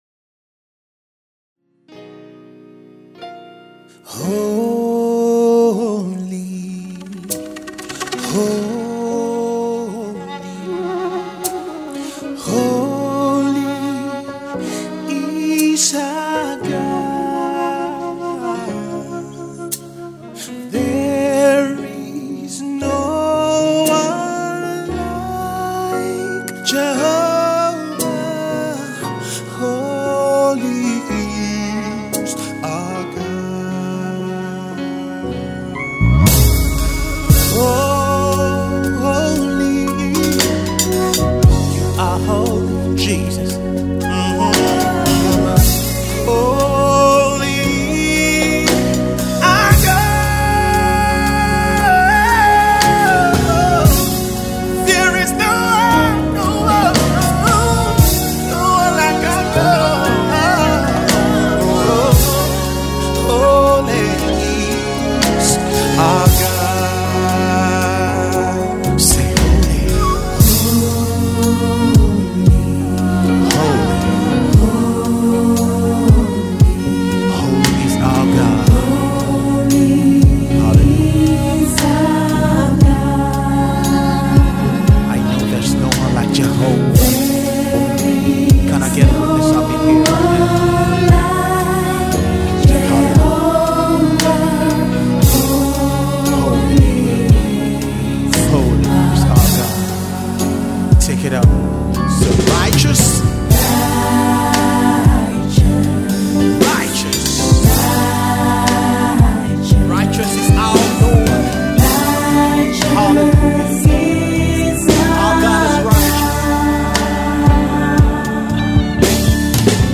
Gospel Singer and worship leader
soul lifting worship single
Guitar
flute/violin
bass guitar